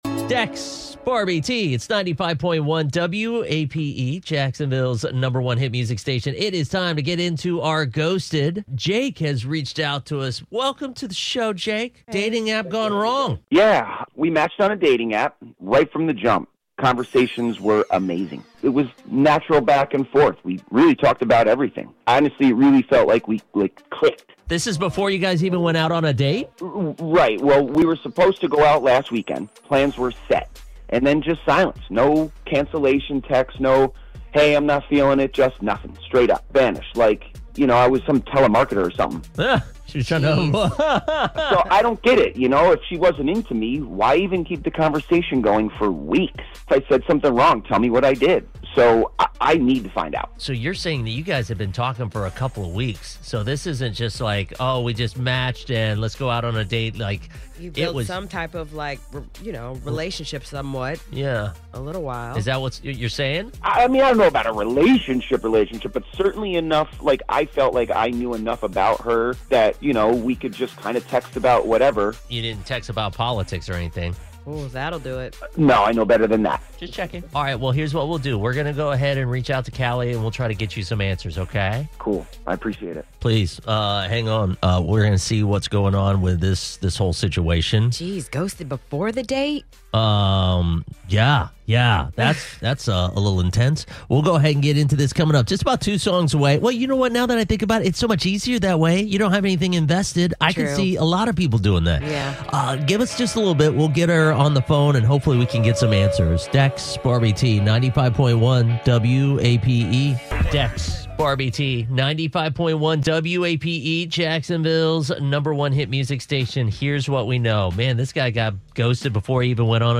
A caller reached out after he was completely ghosted by a girl he met on a dating app.